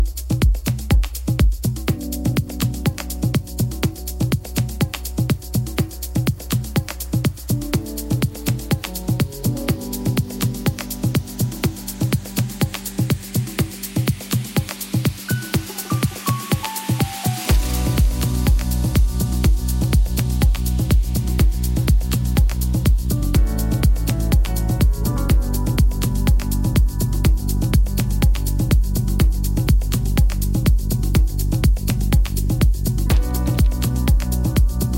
House Dance African Afro-Beat
Жанр: Танцевальные / Хаус